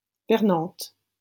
-Vernantes.wav Audio pronunciation file from the Lingua Libre project.